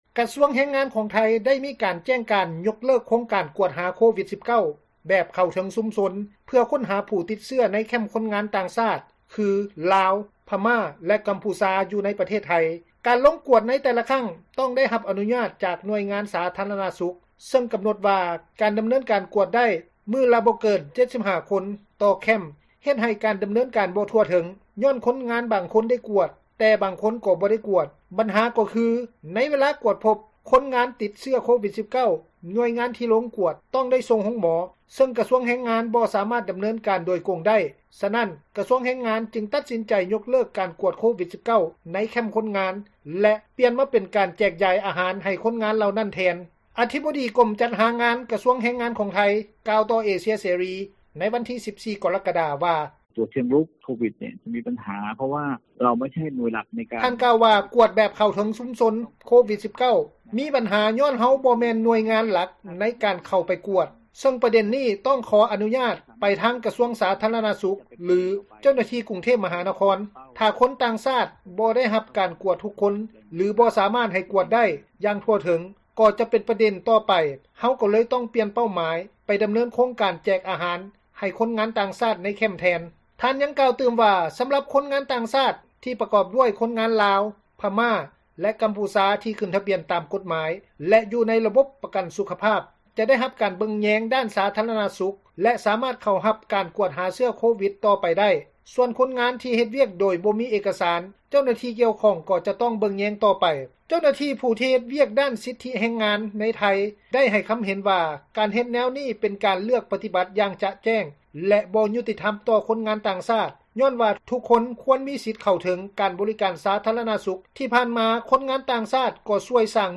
ແມ່ຍິງຄົນງານລາວ ໃນໄທຍ ຄົນນຶ່ງ ກ່າວວ່າ ຢາກໃຫ້ຣັຖບານໄທຍ ຊ່ວຍເຫຼືອຄ່າປິ່ນປົວ ເຖິງວ່າ ຄົນງານລາວຄົນໃດຕິດເຊື້ອອີກ ຄັ້ງກໍຕາມ.